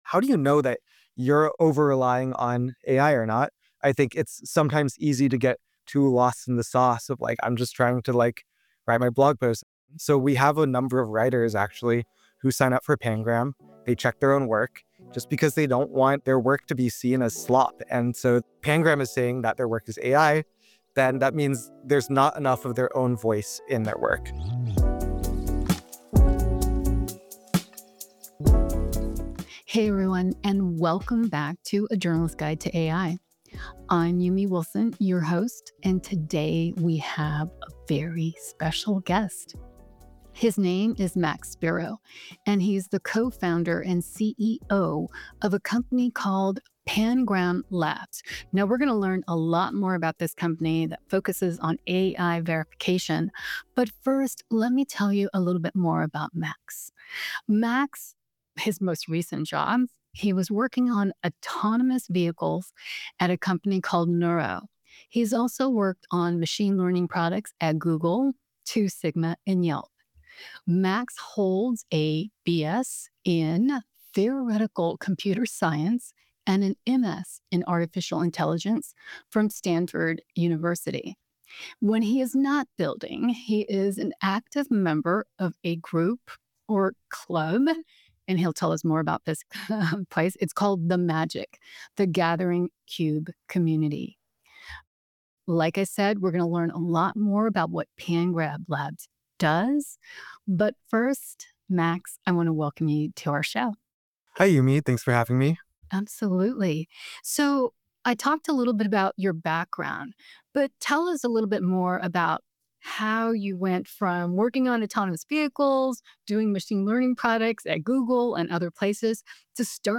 This conversation goes beyond just whether we can detect AI-generated content. We dive into why it matters, for how we learn, how we communicate, and ultimately, how we trust one another.